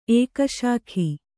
♪ ēkaśakhi